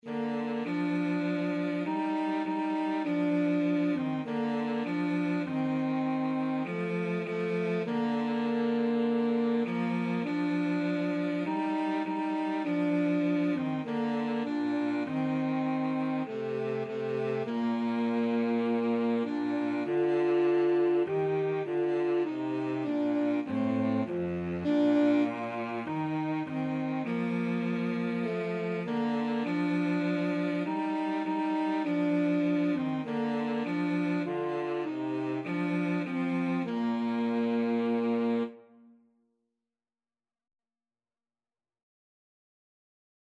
Alto SaxophoneCello
4/4 (View more 4/4 Music)
Classical (View more Classical Saxophone-Cello Duet Music)